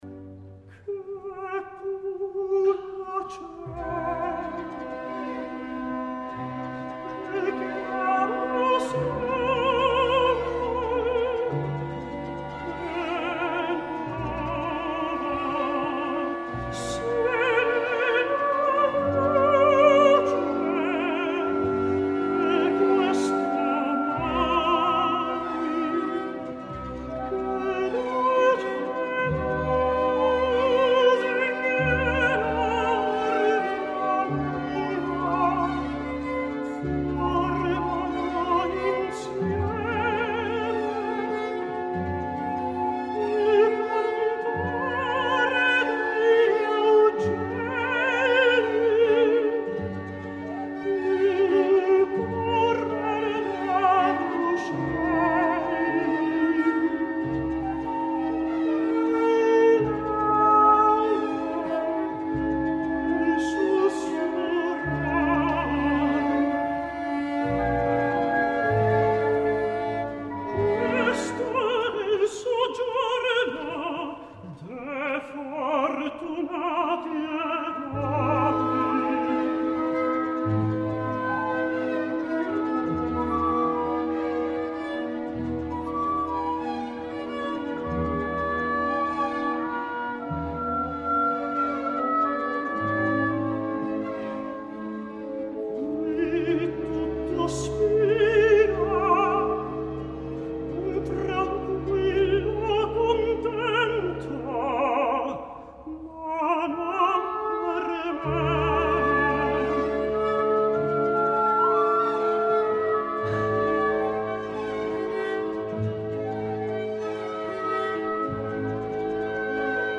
d’estàtics efectes terapèutics en la càlida interpretació